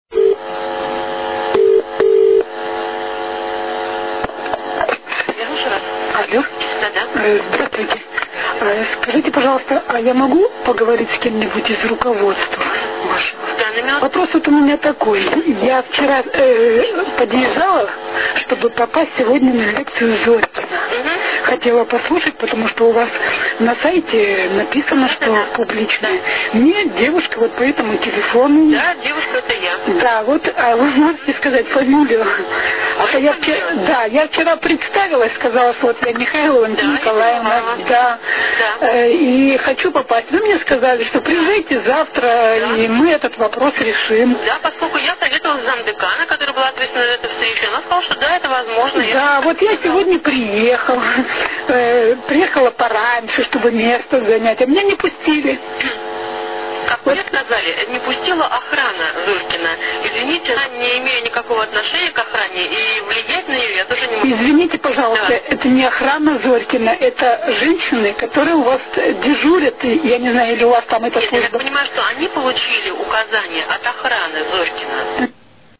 Слушайте объяснения представителя Санкт-Петербургского университета
interview_zorkin.wav